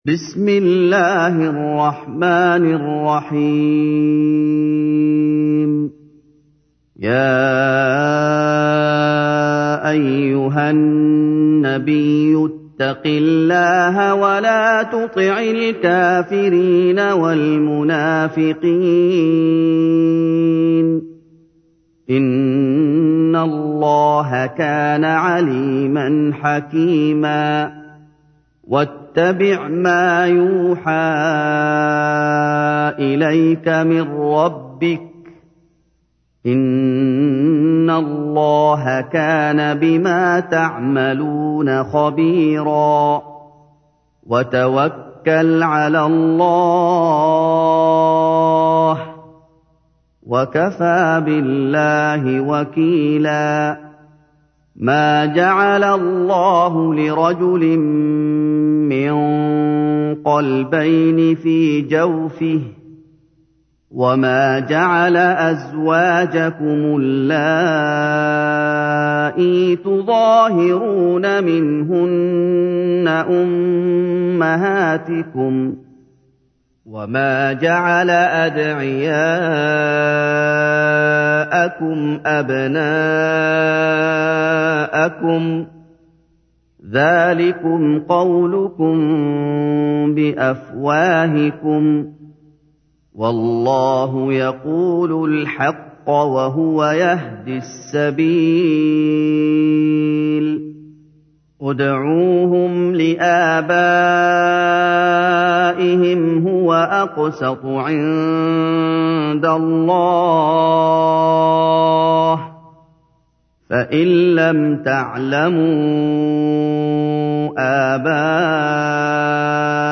تحميل : 33. سورة الأحزاب / القارئ محمد أيوب / القرآن الكريم / موقع يا حسين